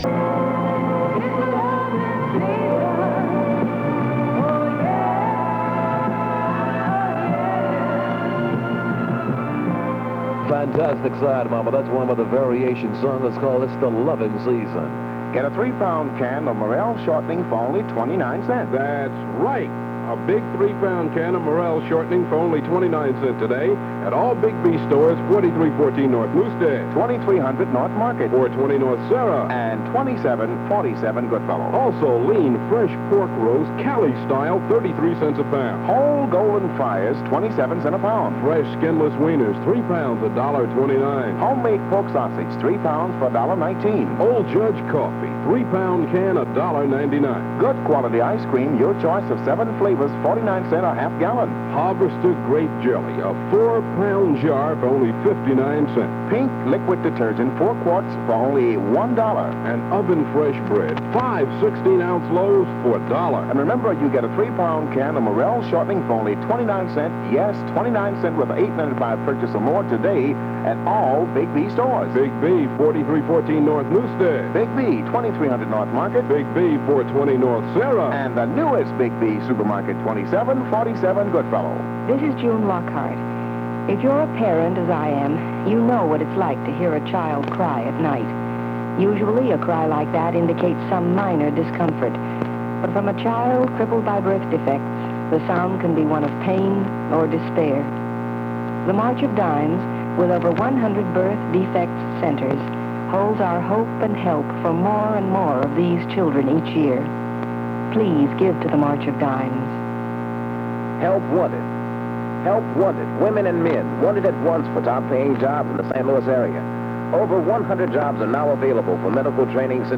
Original Format aircheck